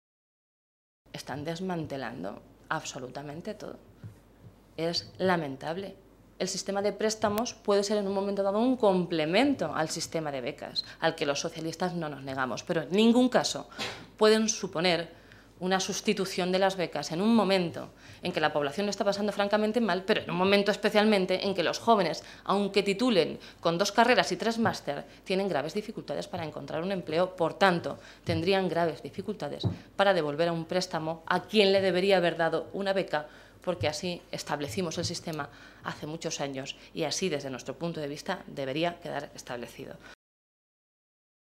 Blanca Fernández, portavoz de Educación del Grupo Parlamentario Socialista
Cortes de audio de la rueda de prensa